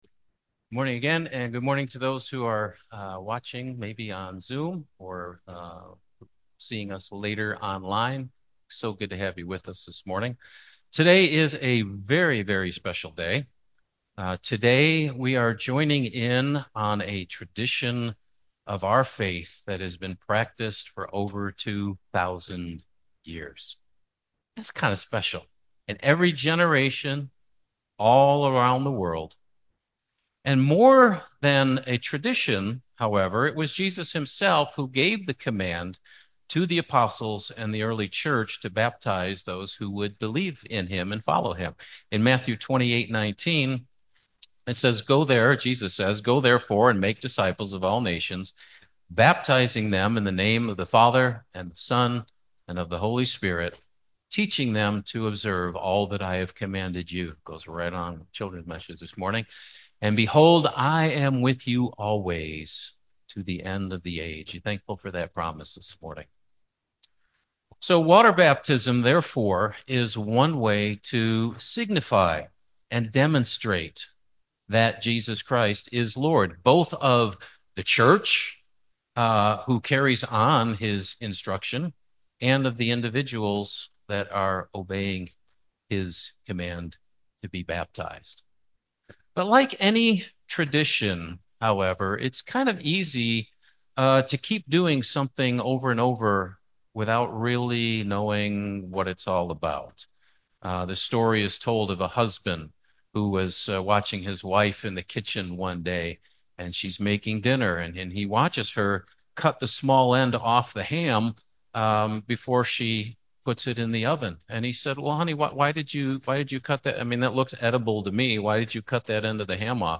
Baptism Service 2025